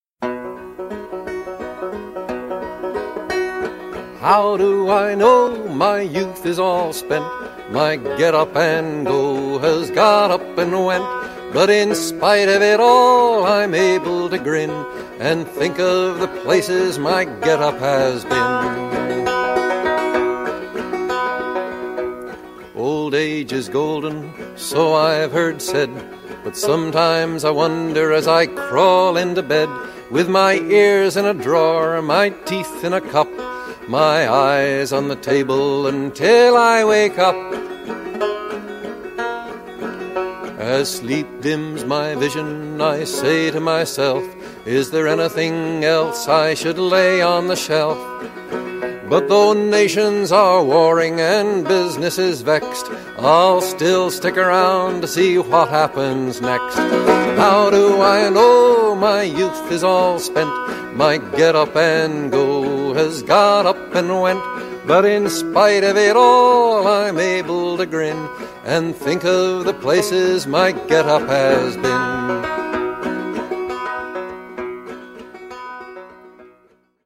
This song is in 3/4 waltz time.